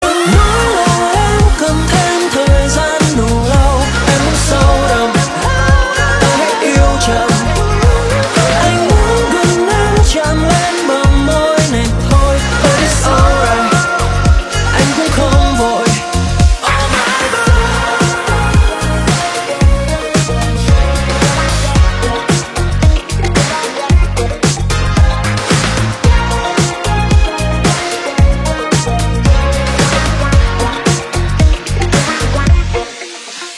Nhạc Chuông Nhạc Trẻ